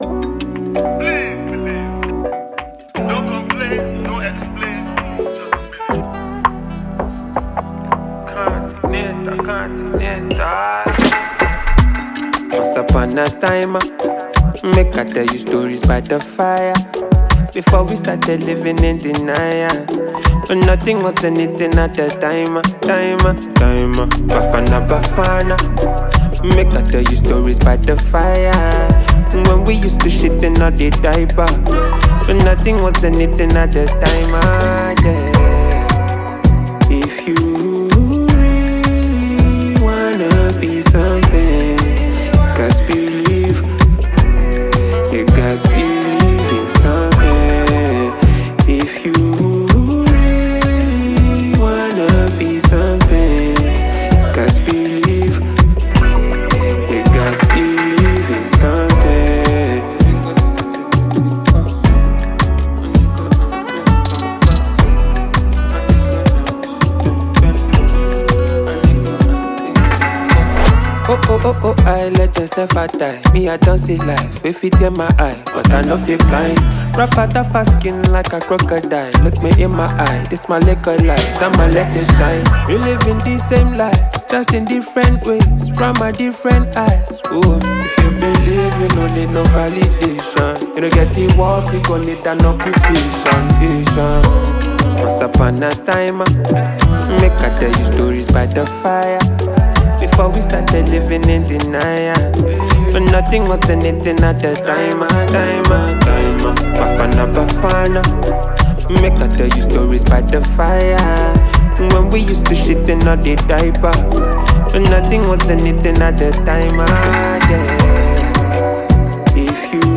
Talented Nigeria singer